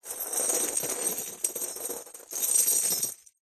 скрежет цепи по полу